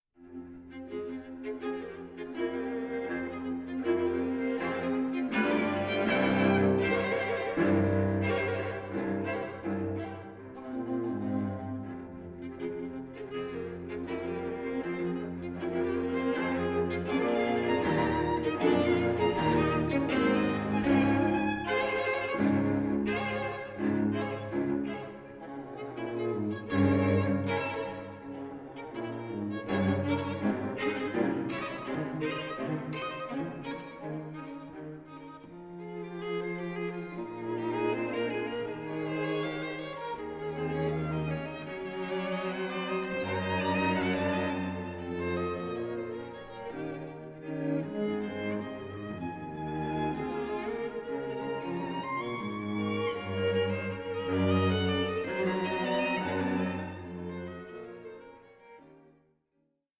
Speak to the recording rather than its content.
recorded live at Kohl Mansion